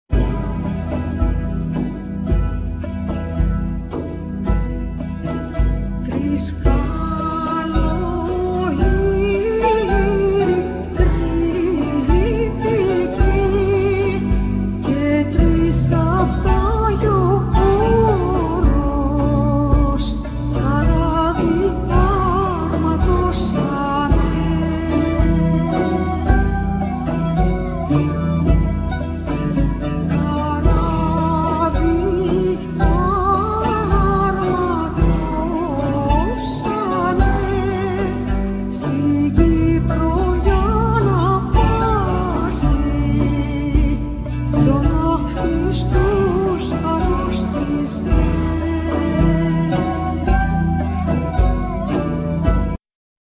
Loud,Mandola,Mandolin,Jura,Percussions
Vocals
Kaval,Gajda
Keyboards
Kemenche